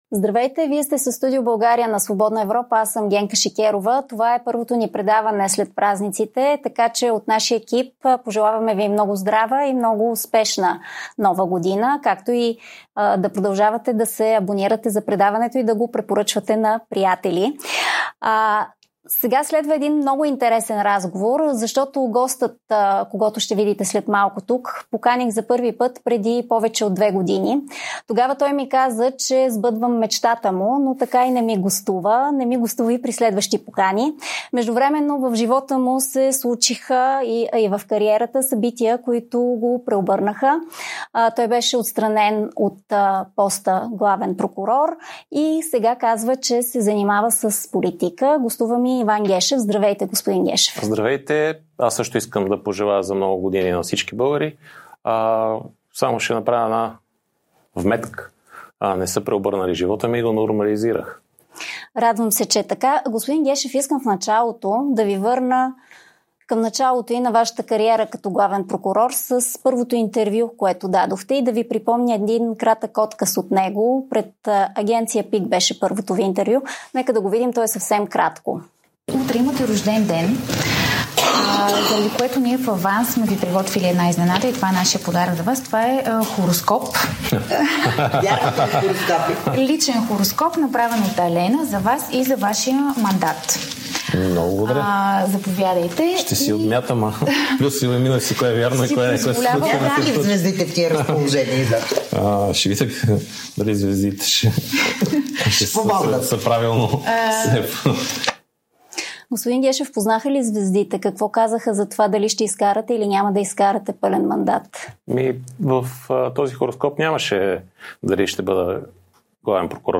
Генка Шикерова задава тези въпроси на бившия главен прокурор Иван Гешев, отстранен от поста през 2023.